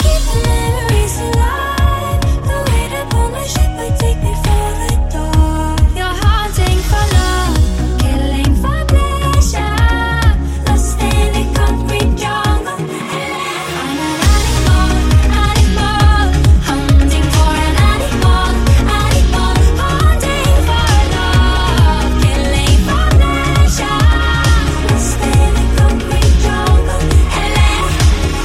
Genere: pop, latin pop, remix